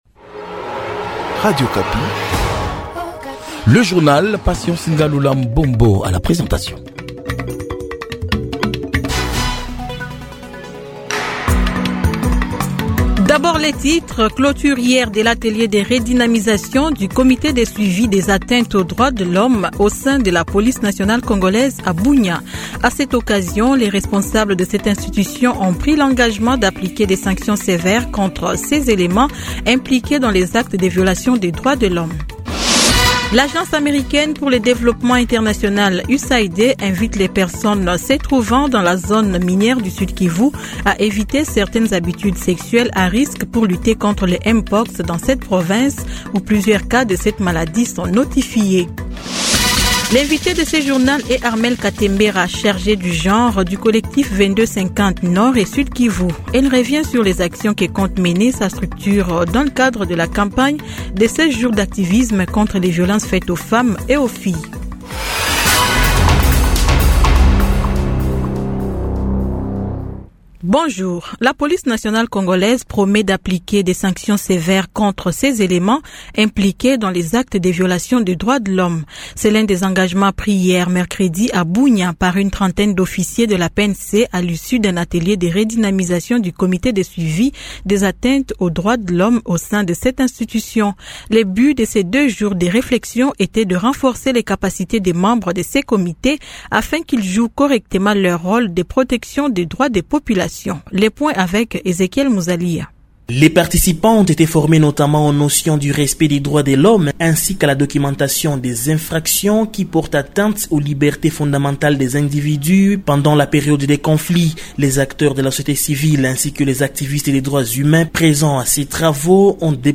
Journal matin 08H00